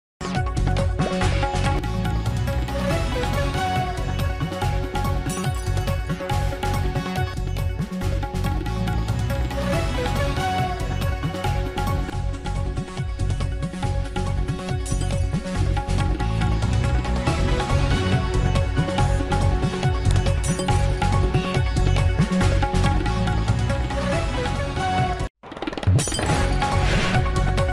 Breaking News Green Screen Format 4K Version